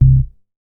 MoogShotLivd 002.WAV